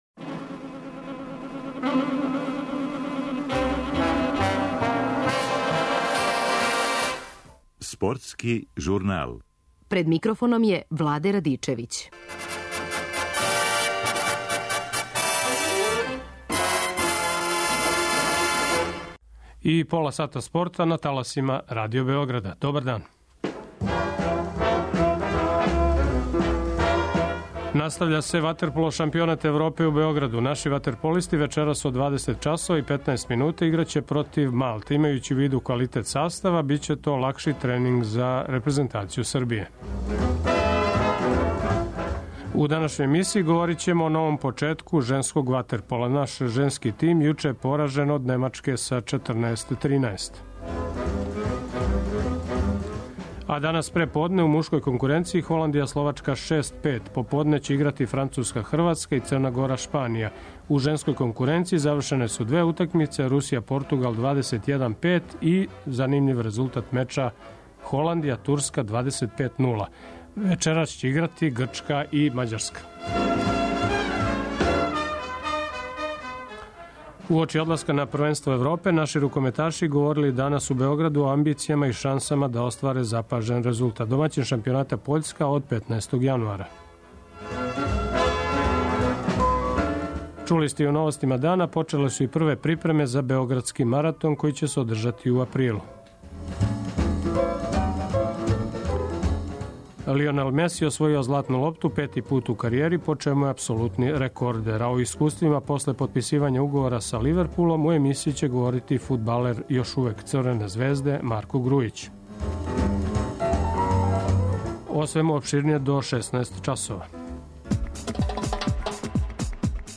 О искуствима после потписивања уговора са Ливерпулом у емисији говори фудбалер Марко Грујић.